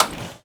R - Foley 188.wav